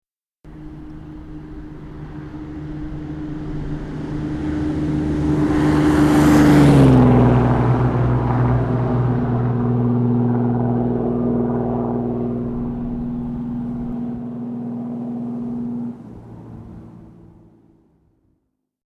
Mobilistin toimituksella oli menneinä vuosina tapana äänittää esiteltyjen ajoneuvojen ääniä.